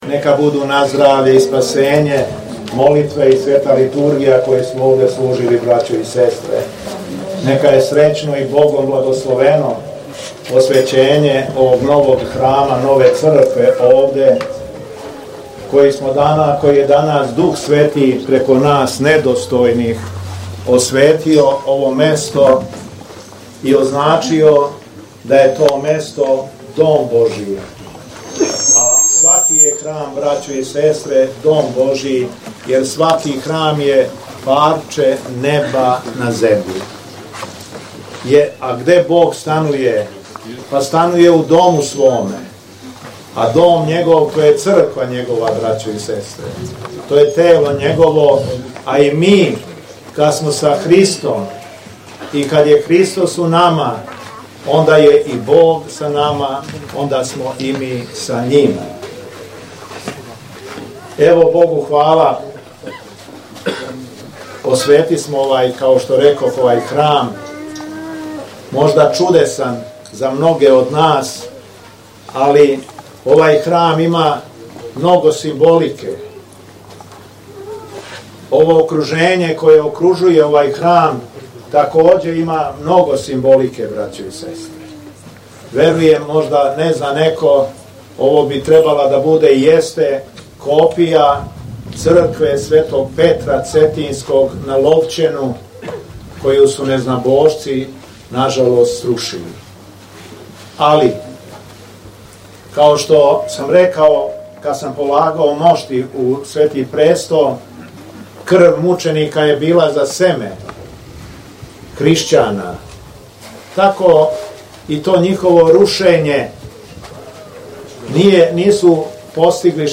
На Светој Литургији на којој се велики број верника причестио, Митрополит Јован је верне поучио својом беседом:
Беседа Његовог Високопреосвештенства Митрополита шумадијског г. Јована